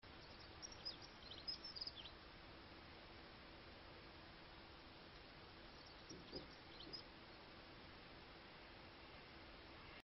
behind a grid,the microphone is inside and captures loud chirps only.
example audio only file 10 sec taken from a movie, coded in 44.1kHz, 128kbps  mp3 format
And the audio also comes from a decent quality small stereo microphone with a little air contact with the outside amplified by the camera, and is first encoded in 320Mbps mpeg2 and then to 192 AAC which should be pretty good.